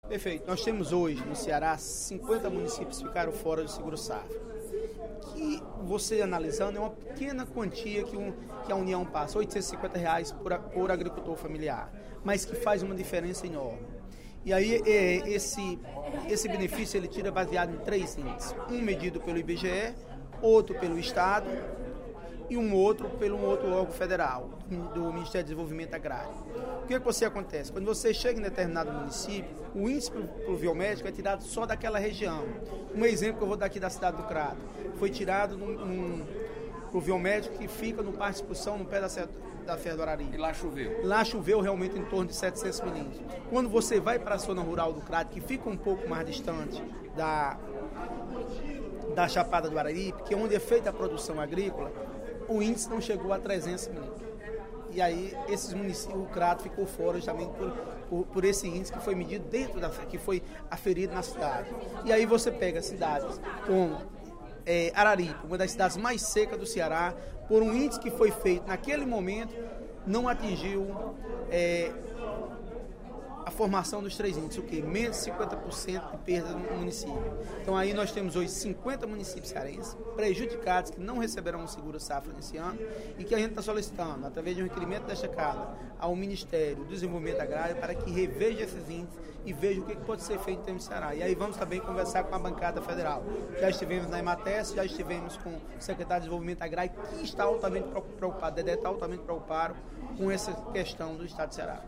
O deputado Zé Ailton Brasil (PP) ressaltou, no primeiro expediente da sessão plenária desta terça-feira (15/12) da Assembleia Legislativa, requerimento, de sua autoria, solicitando ao Ministério do Desenvolvimento Agrário uma releitura dos termos do programa do Governo Federal Garantia-Safra.